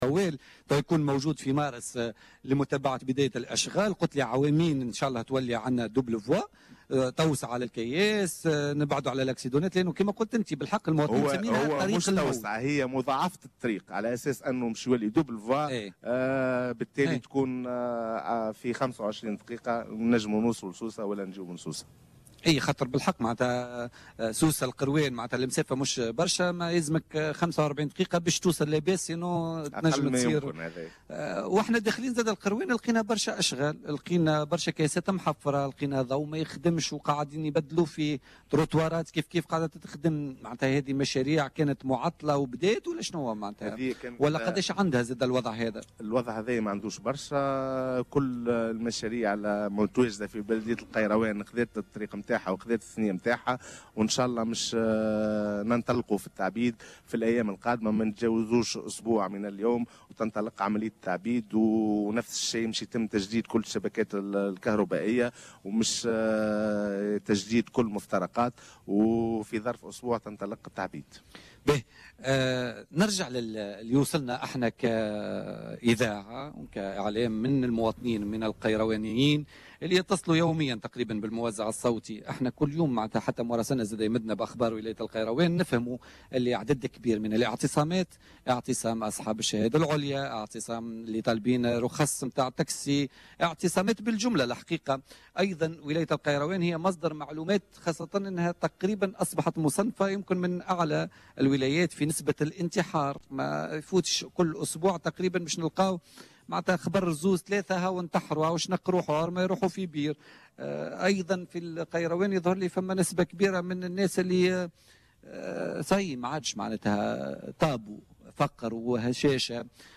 أكد والي القيروان شكري بن حسن ضيف الحصة الخاصة لبوليتيكا اليوم الخميس 18 فيفري 2016 من القيروان أن الوضعية العامة للجهة صعبة للغاية ومؤشراتها ضعيفة خاصة في المعتمديات الداخلية وهناك مشاكل اجتماعية من بينها ظاهرة الإنتحار ما استوجب تكوين لجنة خاصة لمتابعتها مؤكدا أن عديد الإجراءات اتخذت في هذا الخصوص بالاشتراك مع الوزارات والمصالح المعنية.